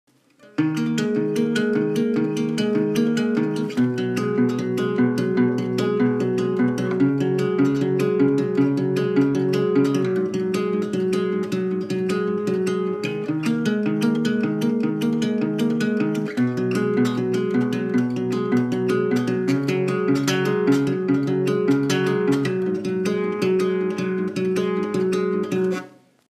arpeggiated intro
featuring a capo on the 3rd fret.
There’s nothing to hide behind on an acoustic guitar!